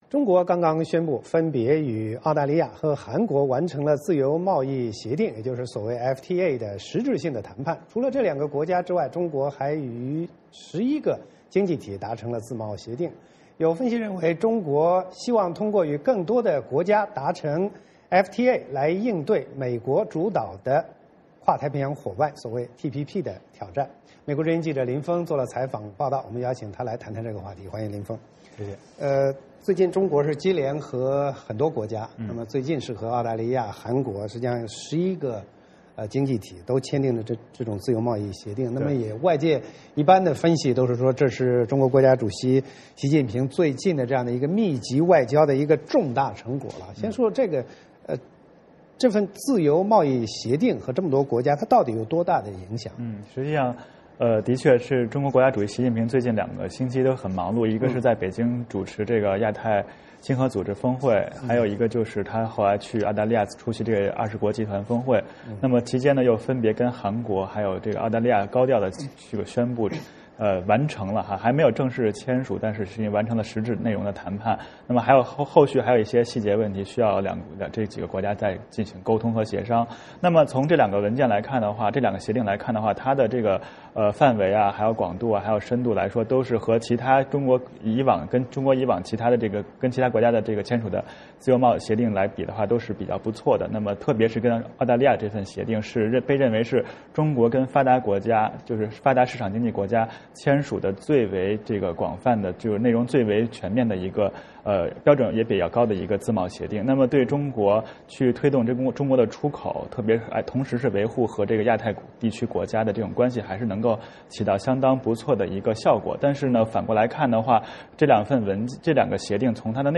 我们邀请他来谈谈这个话题。